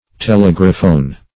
Search Result for " telegraphone" : The Collaborative International Dictionary of English v.0.48: Telegraphone \Te*leg"ra*phone\, n. [Gr. th^le far + -graph + ? sound.]